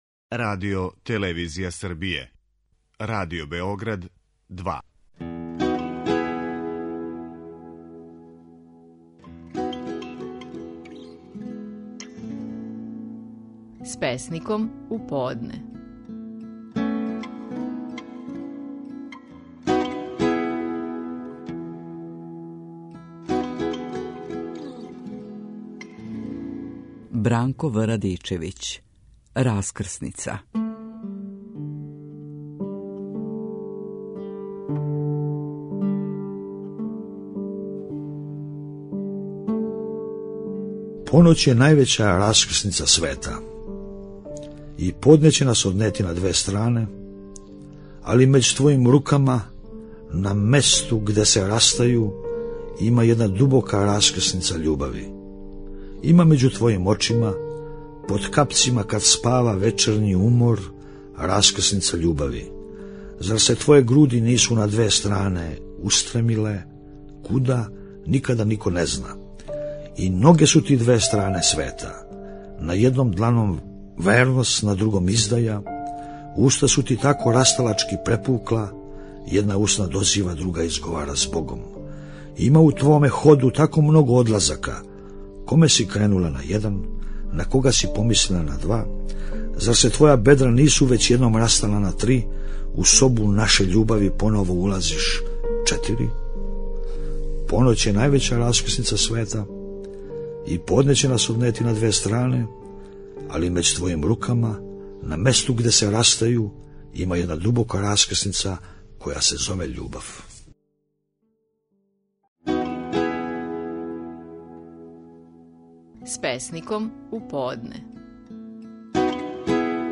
Стихови наших најпознатијих песника, у интерпретацији аутора.
Бранко В. Радичевић говори стихове песме „Раскрсница".